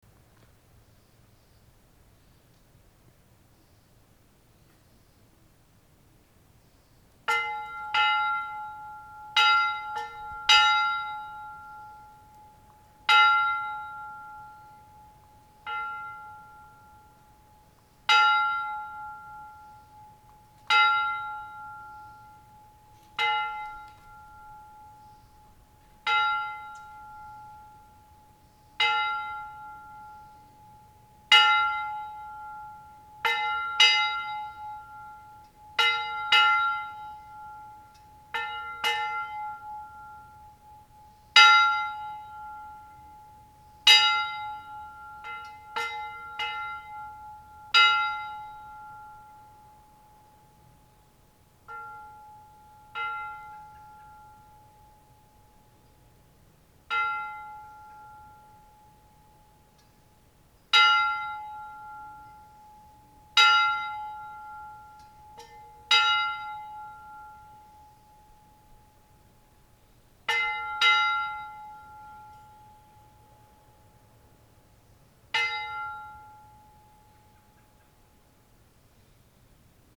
9/16 AM Sunrise at the Marin Headlands
I have been trying to record large bells outdoors in a quiet environment for the past few days without much success.
This morning we drove out to the Marin Headlands. It’s relatively quiet out there and I was hoping that it would be early enough in the morning the world would just be stirring and perhaps not yet firing up the grand and ubiquitous combustible engine.
audio I recorded while it was still dark.